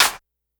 snr_06.wav